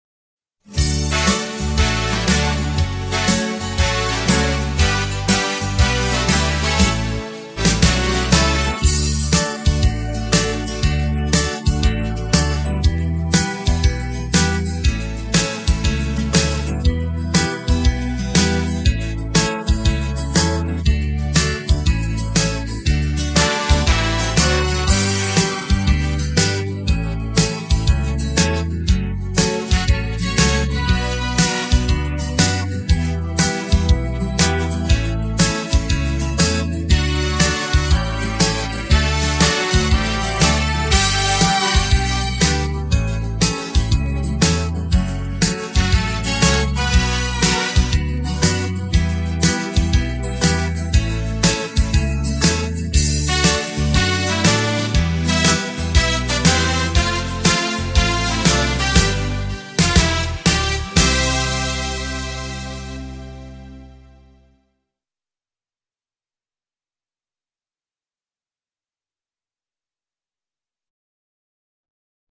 instrumentaal